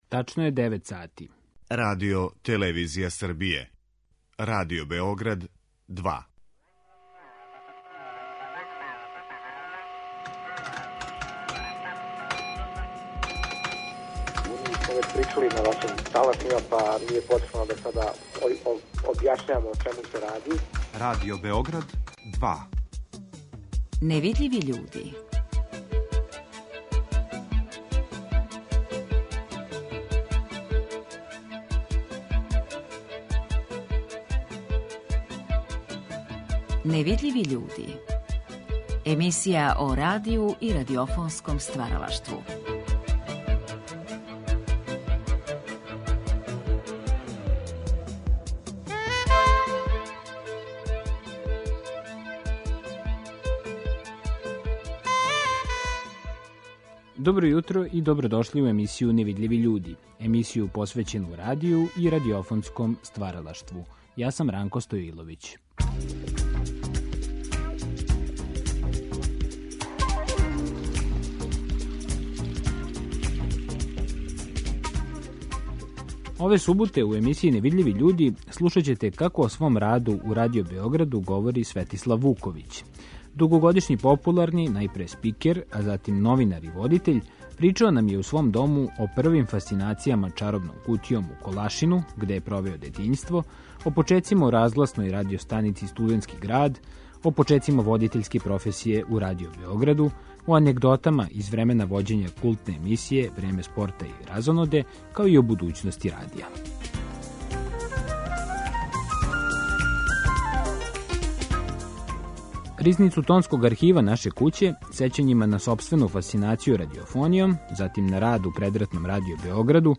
Дугогодишњи популарни новинар и водитељ причао нам је у свом дому о првим фасцинацијама "чаробном кутијом" у Колашину, где је провео детињство, о почецима у разгласној радио-станици "Студентски град", о анегдотама из времена вођења култне емисије "Време спорта и разоноде"...